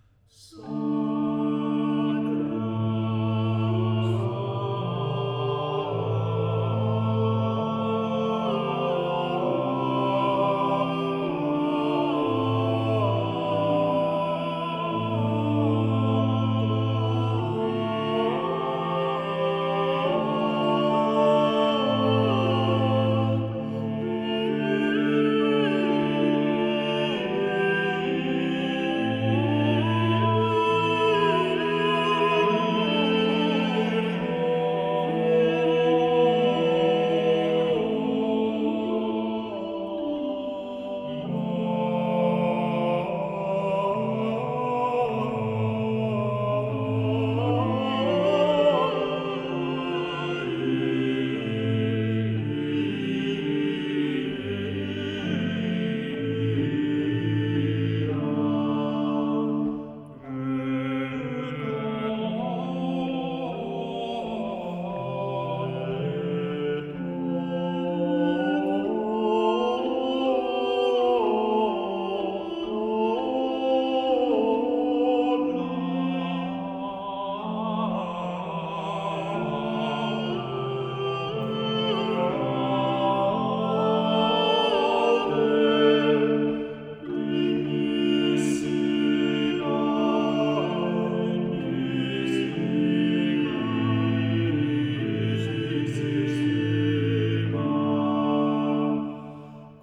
4 voces (Mezzo; Tenor; Tenor; Bajo)